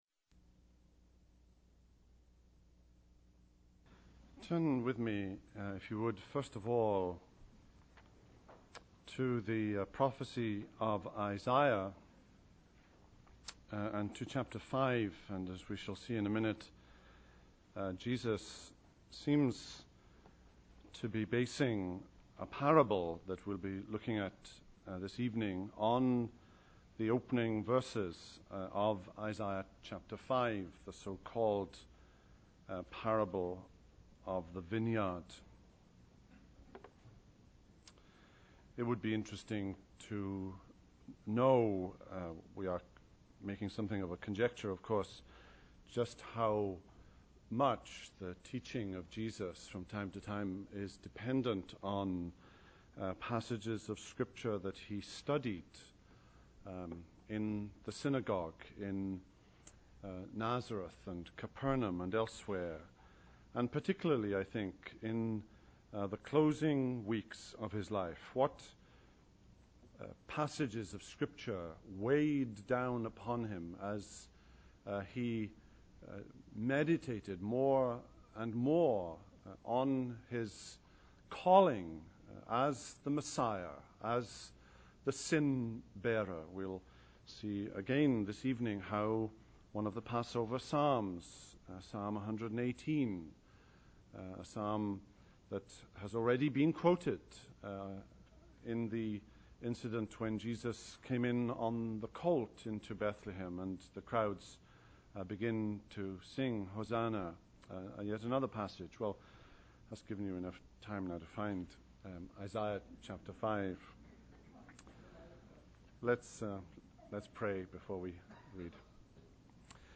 Listen Download Audio Print This Post Wednesday Evening October 5, 2005 Mark 11:27-12:12 “The Napa Valley Parable!”